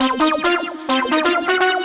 FLUTISH ARZ 1.wav